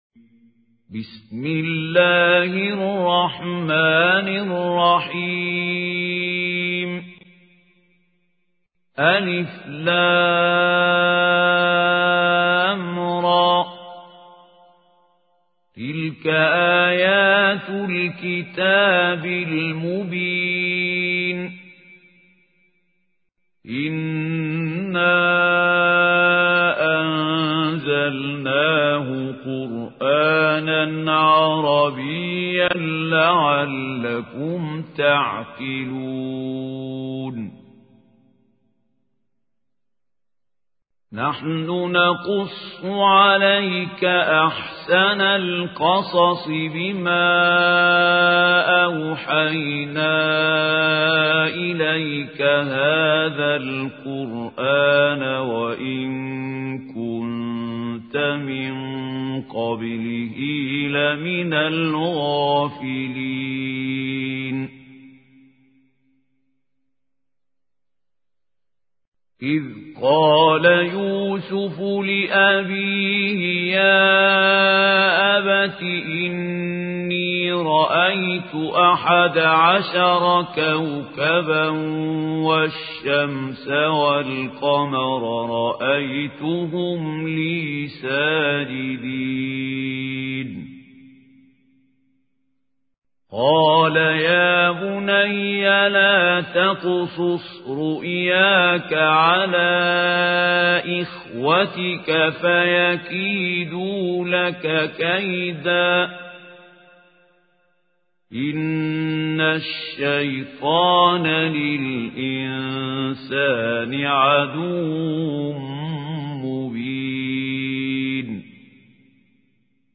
اسم التصنيف: المـكتبة الصــوتيه >> القرآن الكريم >> الشيخ خليل الحصري
القارئ: الشيخ خليل الحصري